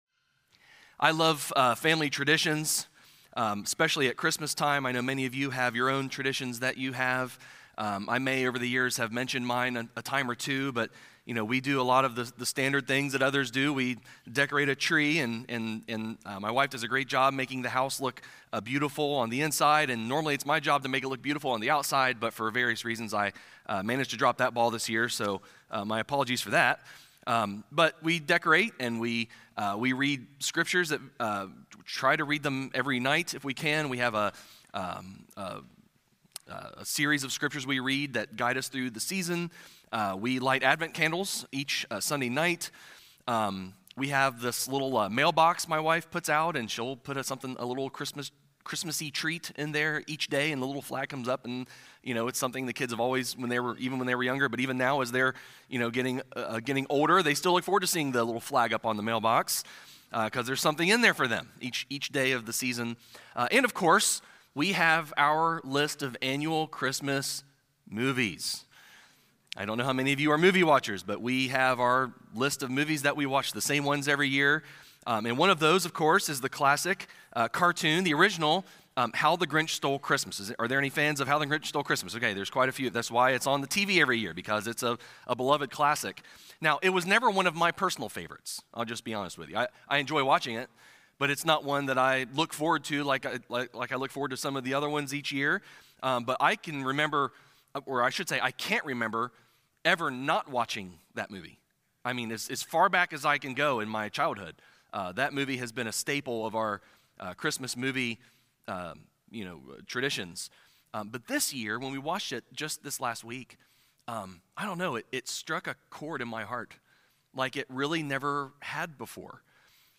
Listen to the latest sermons, Bible studies, and more from Elizabeth City Evangelical Methodist Church.